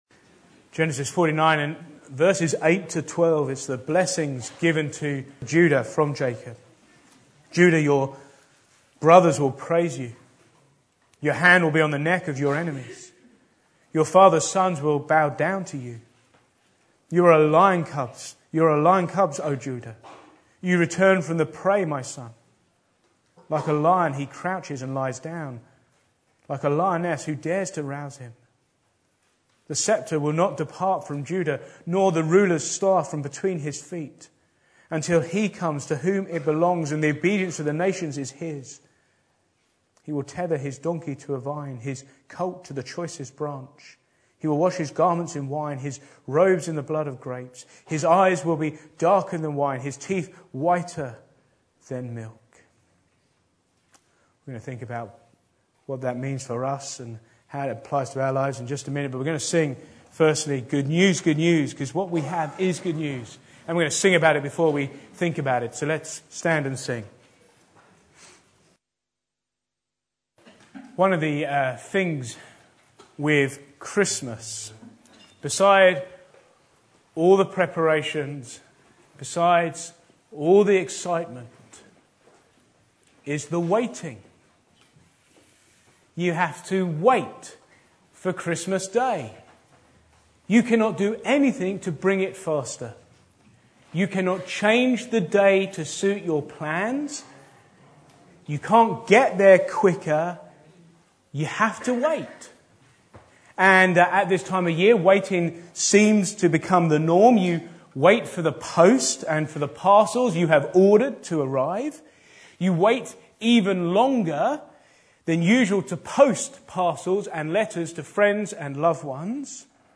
Back to Sermons Waiting for Christmas